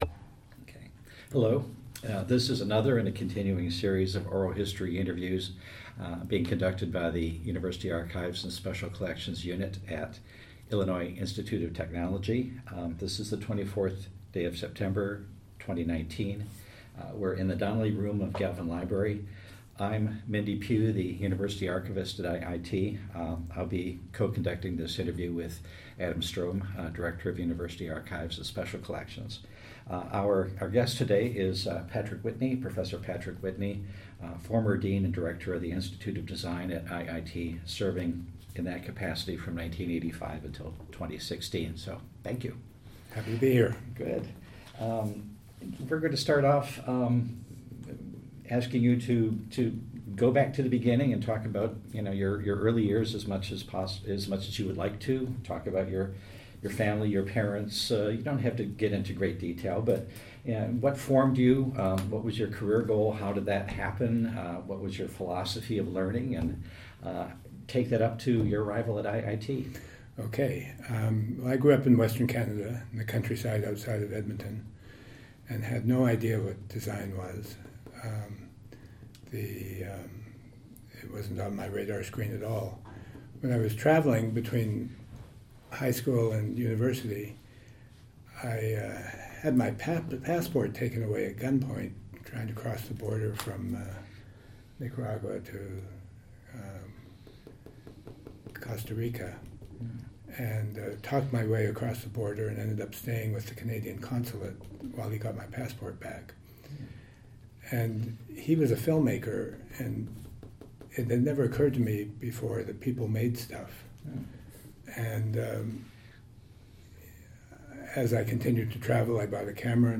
Type Interview